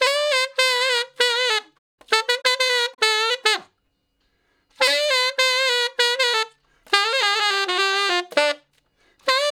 066 Ten Sax Straight (D) 11.wav